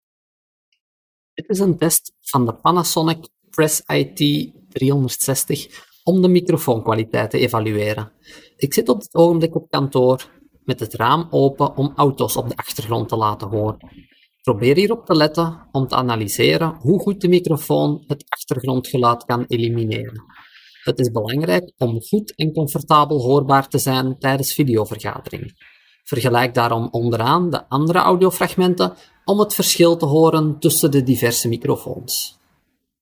The sound quality of the microphone is also quite good. Below you can listen to a recording of the microphone and compare it with other solutions.